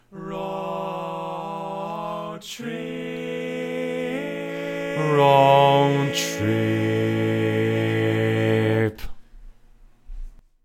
Key written in: A Major
How many parts: 4
Type: Barbershop